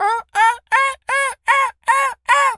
seagul_squawk_seq_08.wav